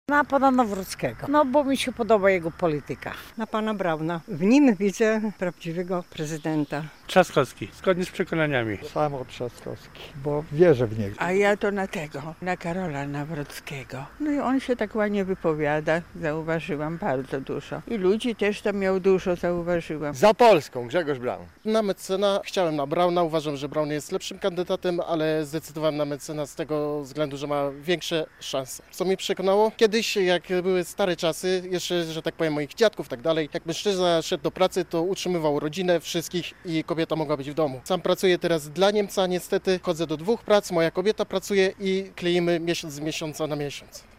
Na kogo głosowali mieszkańcy Łomży? - relacja